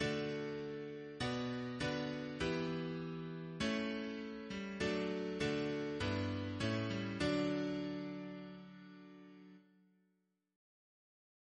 Single chant in Ddesc Composer: F. A. Gore Ouseley (1825-1889) Note: Ouseley in D with descant Reference psalters: ACB: 36; CWP: 219; H1940: 677; H1982: S198; OCB: 246; PP/SNCB: 46; RSCM: 203